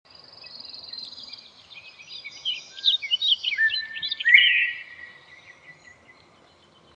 Here in the U.S., the Northern Bobwhite’s call is very familiar.